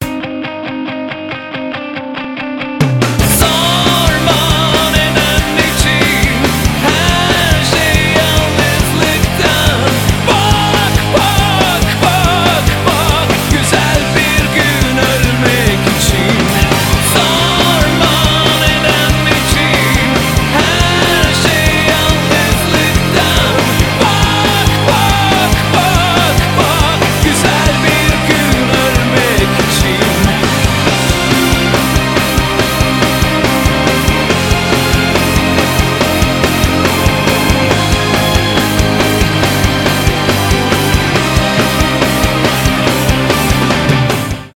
pop rock
рок